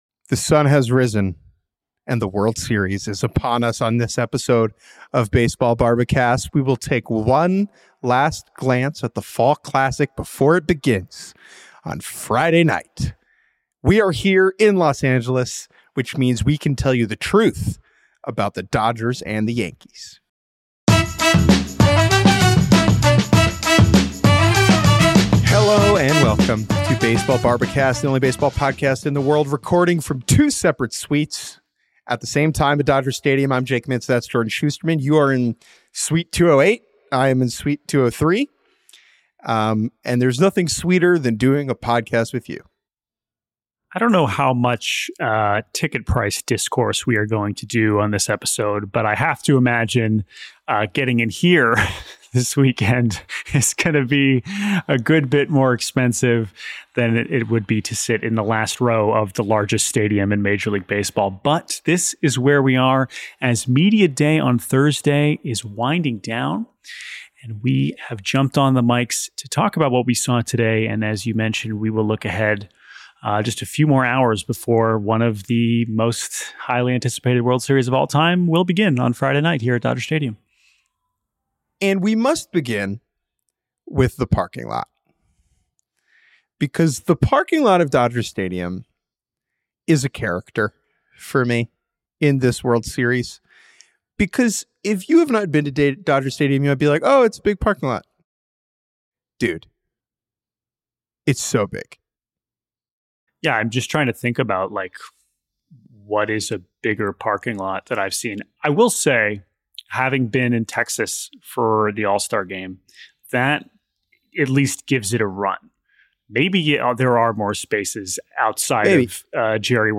Recording from right inside Dodger stadium